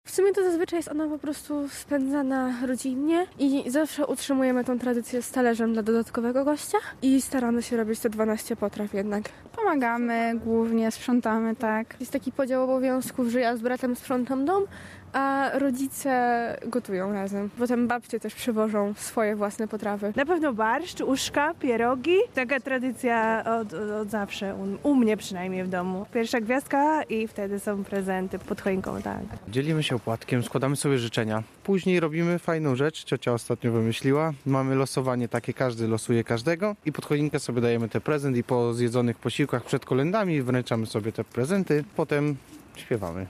Sonda świąteczna w Rzeszowie
Jak wyglądają przygotowania i sama Wigilia w naszych domach? Zapytaliśmy o to mieszkańców Rzeszowa: